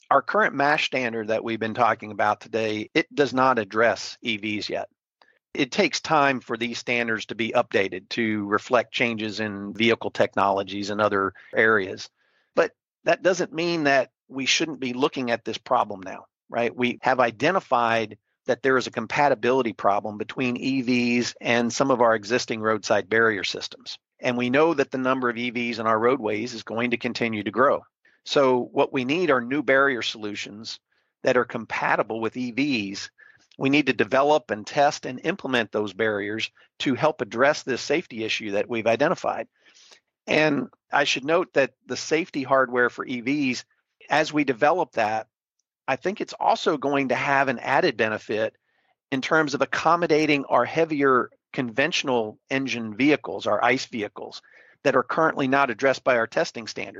To hear the whole interview and more like it just log onto the Thinking Transportation homepage or find the episode wherever you get your podcasts.